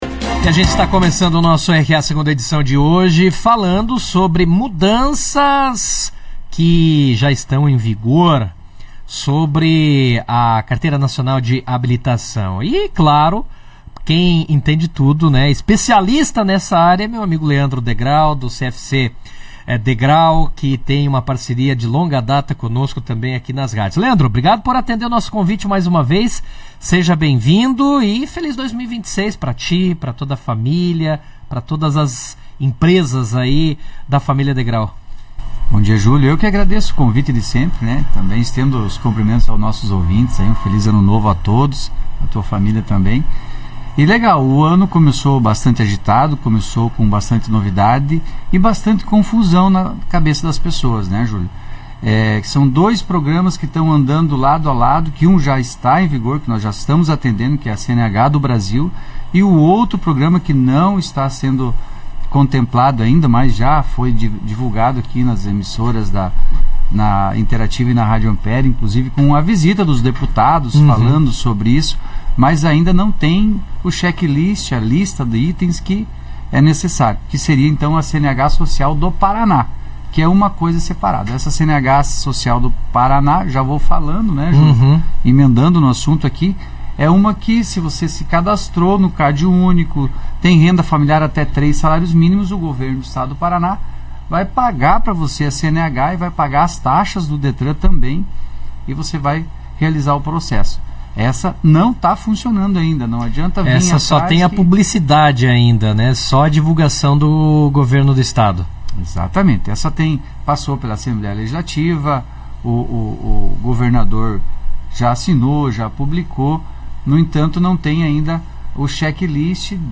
Mudanças na CNH, CNH Social e novas regras para ciclomotores são tema de entrevista no Jornal RA - Rádio Ampere